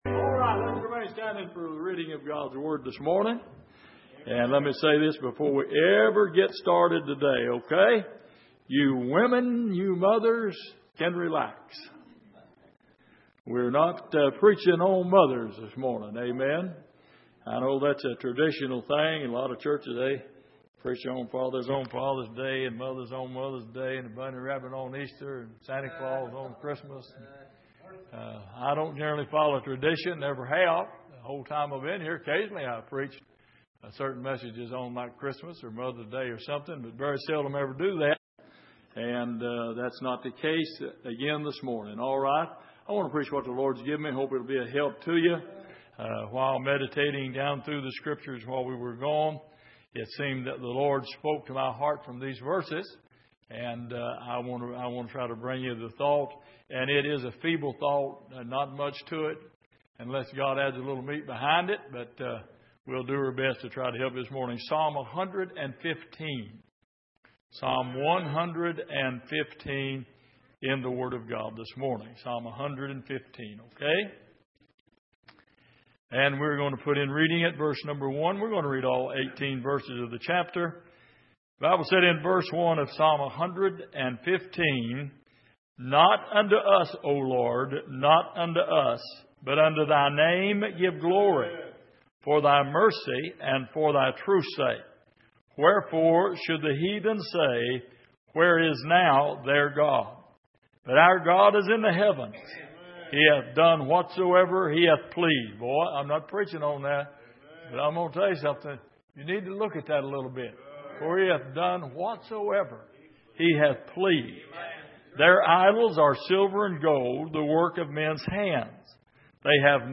Exposition of the Psalms Passage: Psalm 115:1-18 Service: Midweek Trusting In The Lord « Companions Of Salvation What Is A Chistian?